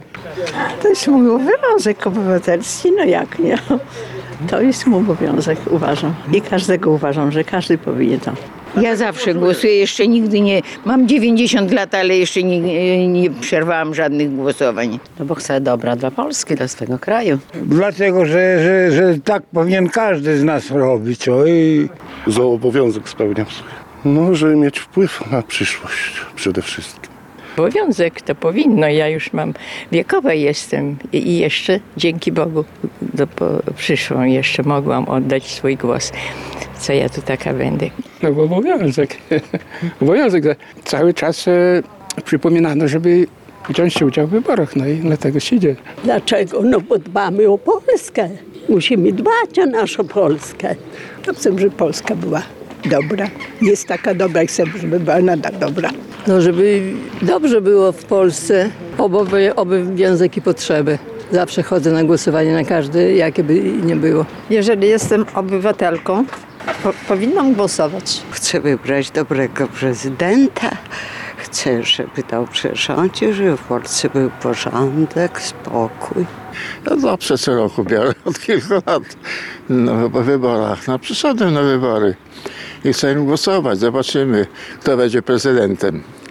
– Chcemy zmian, a udział w wyborach to nasz obywatelski obowiązek. – mówią przechodnie spotkani przed lokalami wyborczymi w Suwałkach, biorący udział w dzisiejszych wyborach prezydenckich.